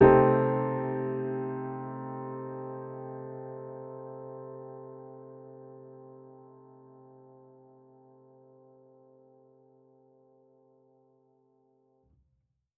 Index of /musicradar/jazz-keys-samples/Chord Hits/Acoustic Piano 1
JK_AcPiano1_Chord-Cm6.wav